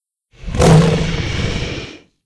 c_hydra_atk1.wav